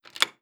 SafeUnlock.wav